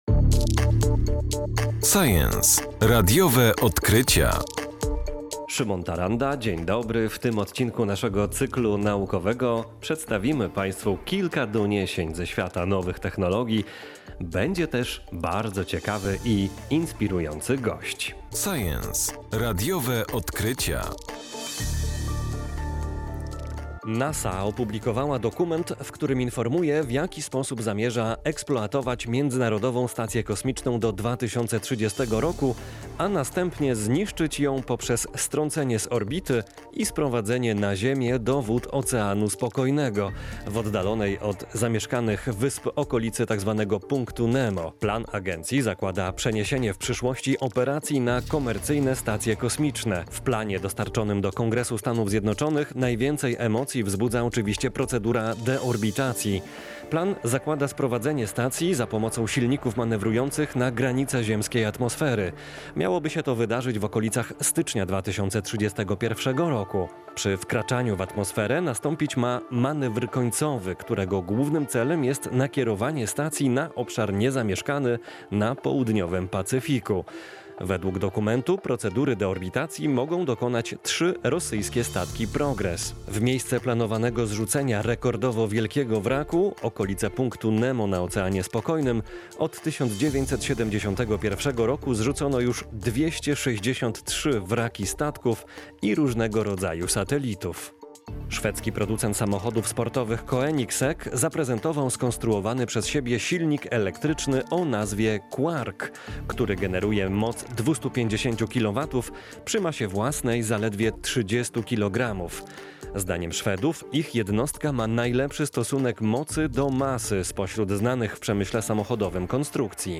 Dlaczego i jak NASA zniszczy swoją stację kosmiczną na orbicie okołoziemskiej? Jak robić dobre zdjęcia i filmy z drona? O tym dziś porozmawiamy w naszym naukowym cyklu.